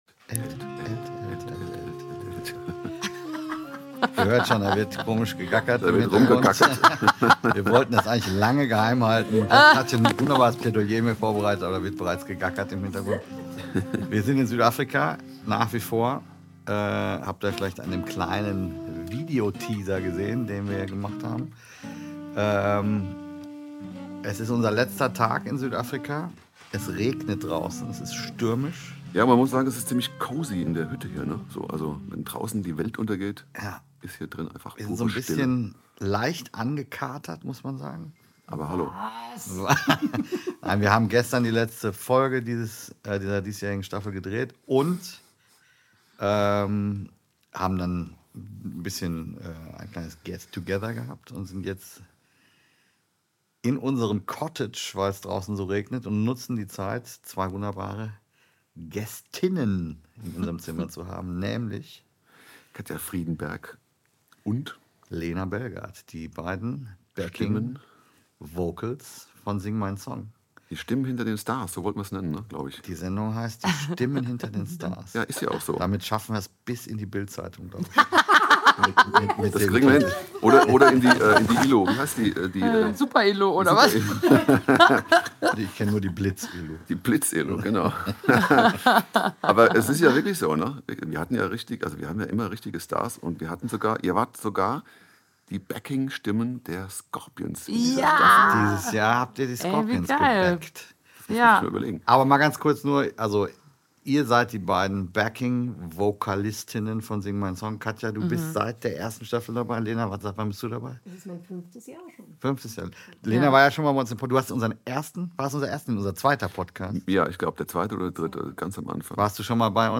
kommt direkt aus Südafrika